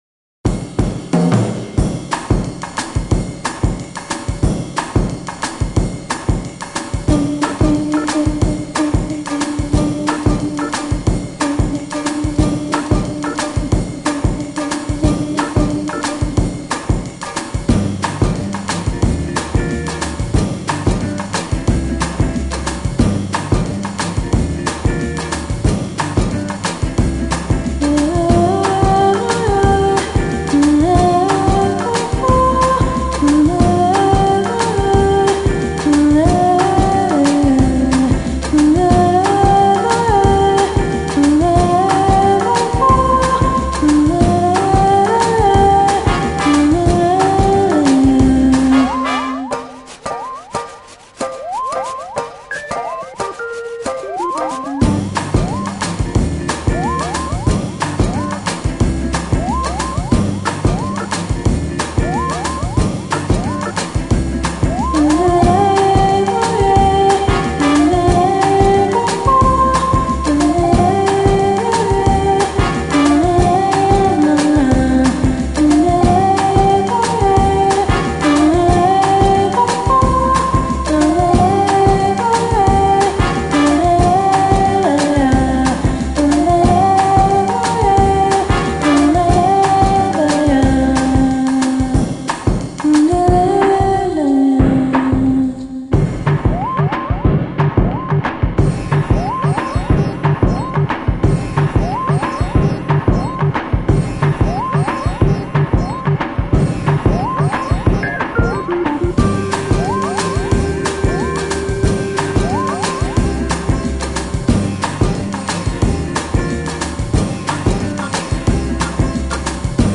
Posted in jazz, secret agent on March 1st, 2007 2 Comments »
Acid Jazz
The music here is strong and steady.